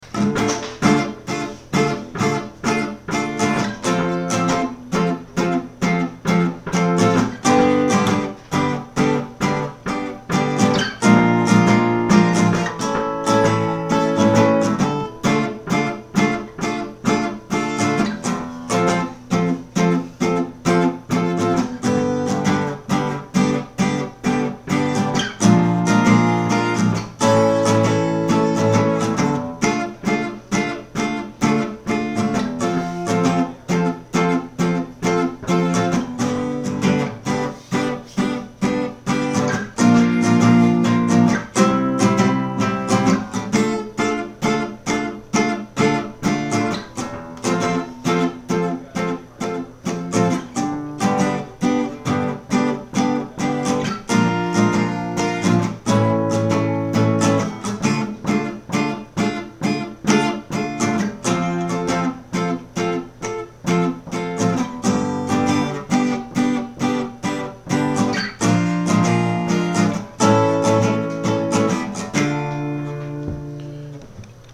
prospective verse a couple times